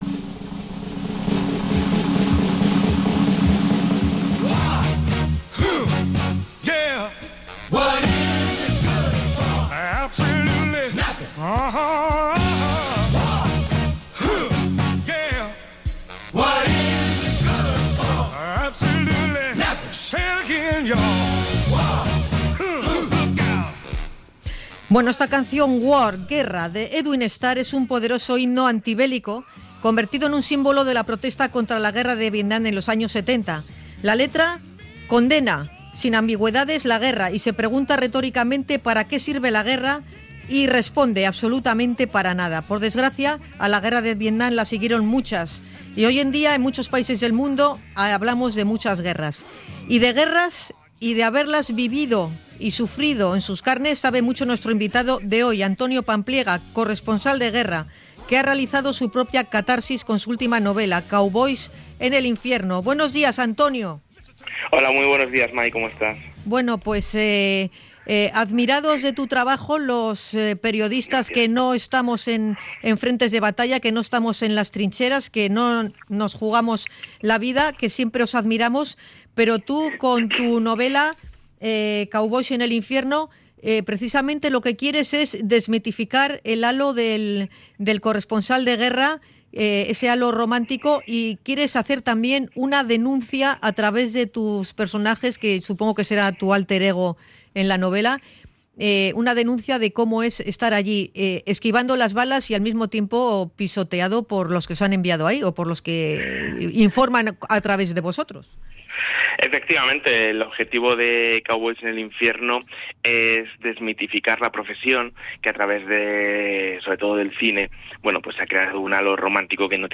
Hablamos con Antonio Pampliega, reportero de guerra